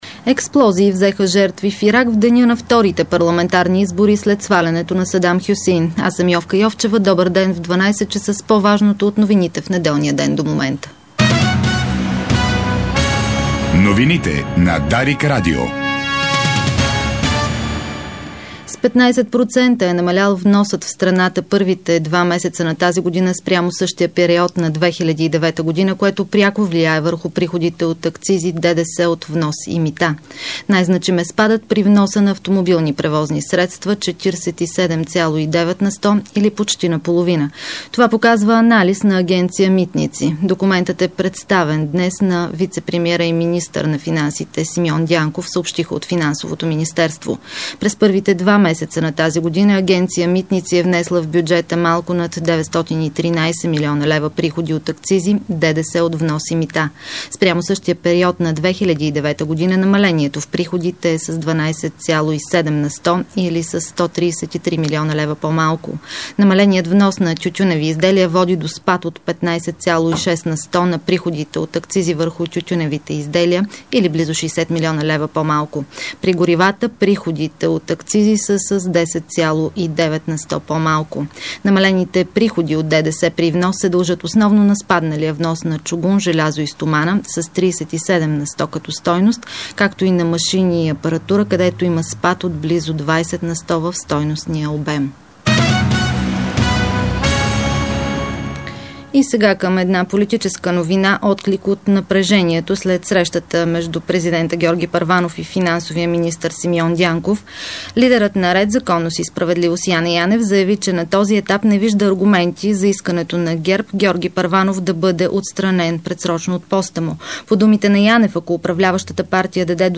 Обедна информационна емисия - 07.03.2010